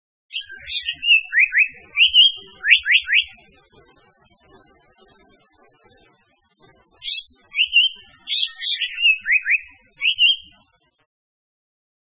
2125「鳥の鳴声」
〔サンコウチョウ〕ギッギッ／ツーキーヒーホシ，ホイホイホイ（さえずり）／低山以
sankoucyo.mp3